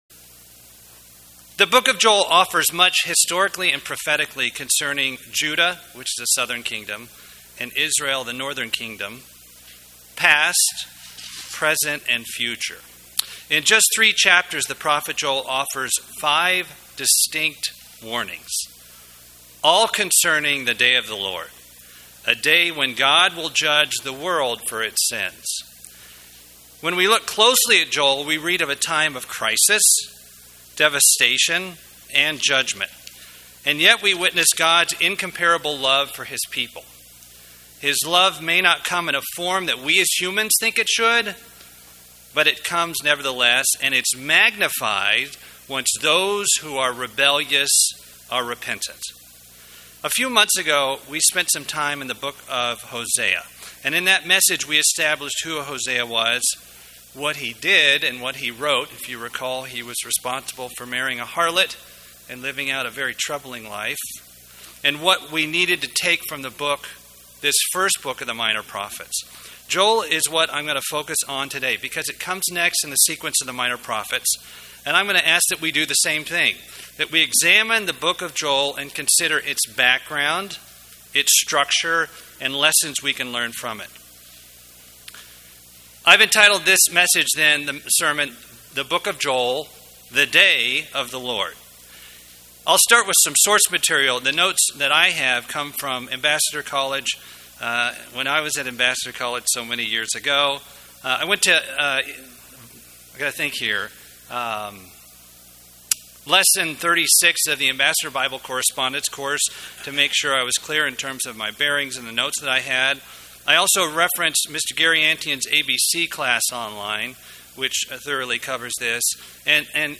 Given in Atlanta, GA Buford, GA
UCG Sermon Studying the bible?